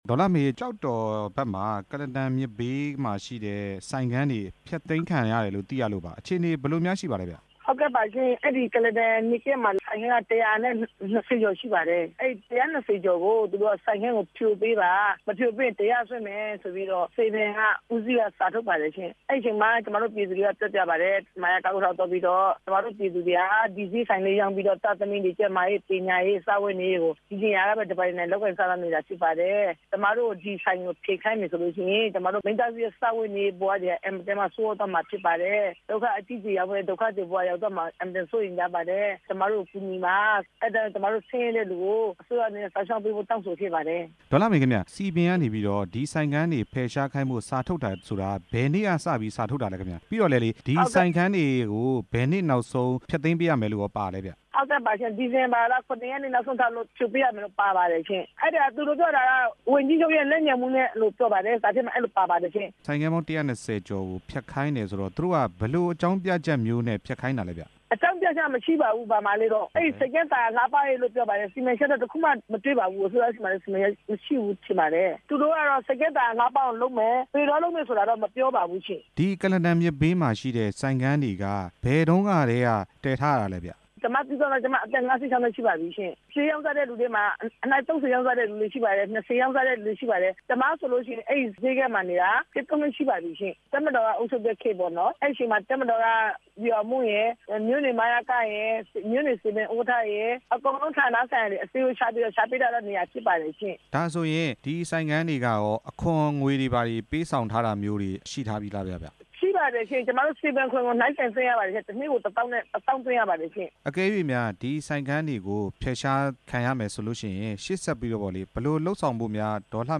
ကုလားတန်မြစ်ဘေးက ဈေးဆိုင်ခန်း တစ်ရာကျော်ကို ဖယ်ရှားခိုင်းနေတဲ့အကြောင်း မေးမြန်းချက်